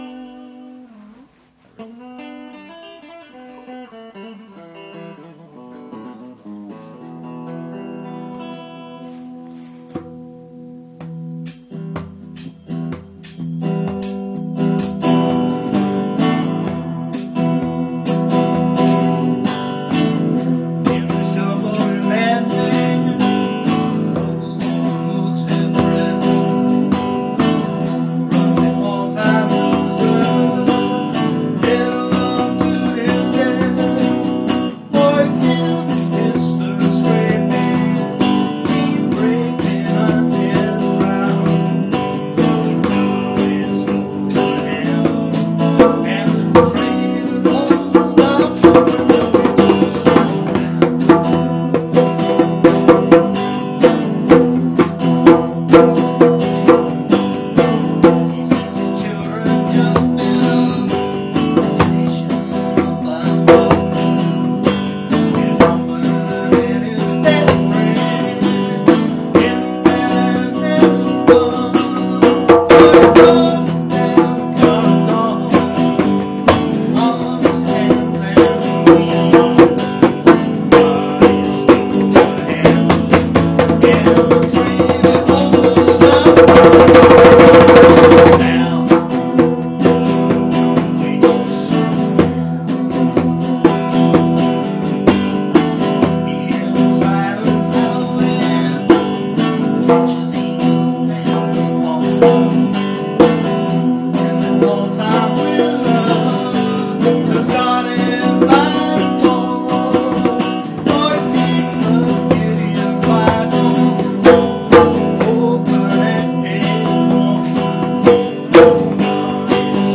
I know… it does sound a bit rough
recorded in my lounge
the very pinnacle of English folk-rock…